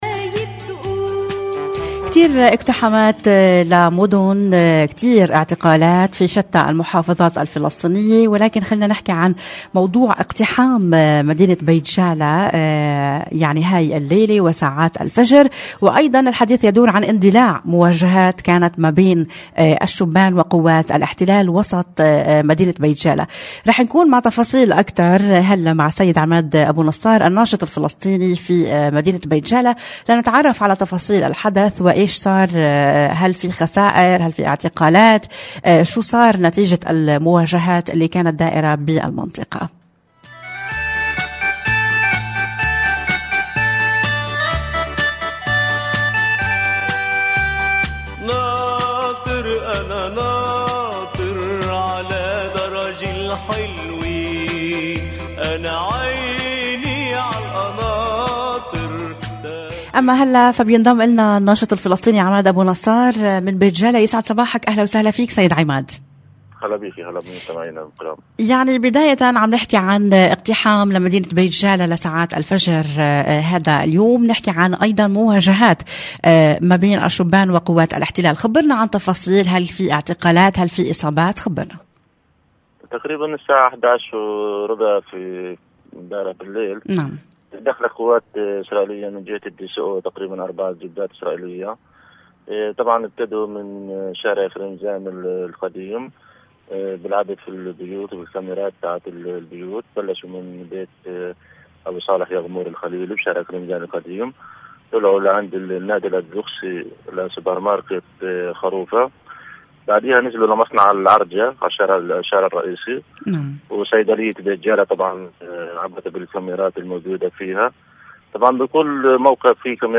راديو موال-استضاف راديو موال في برنامج”دواوين البلد”